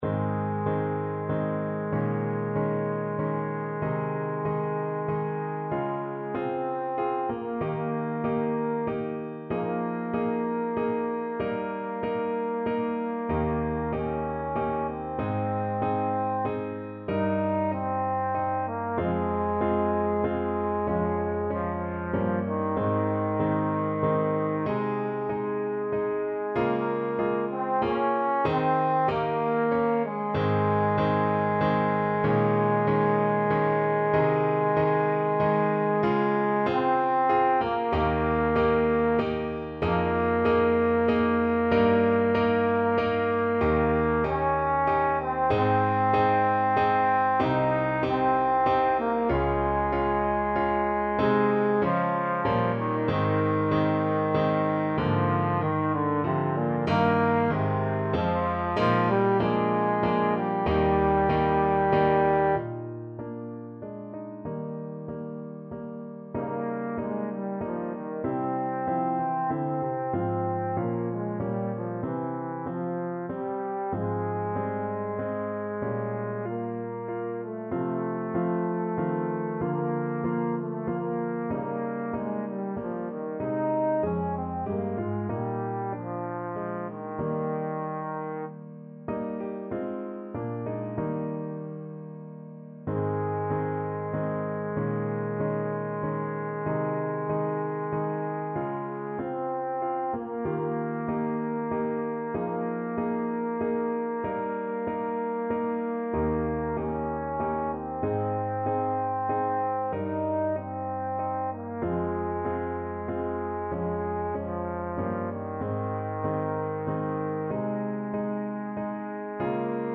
3/4 (View more 3/4 Music)
=95 Andante
Classical (View more Classical Trombone Music)